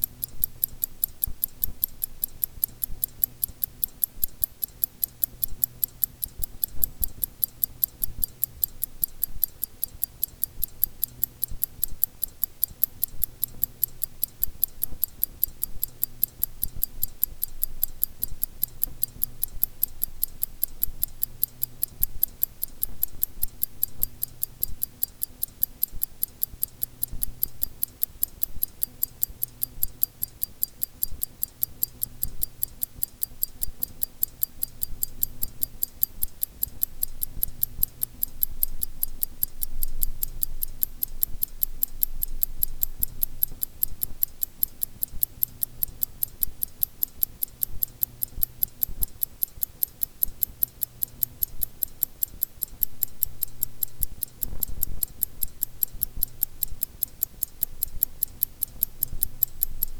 This generates a different ticking pattern between consecutive ticks.
The white noise and the low frequency noise are also quite visible in the spectrogram, and also easy to notice when listening to the sound produced by the time series.
Unfiltered ticking sound
unfiltered-ticking.mp3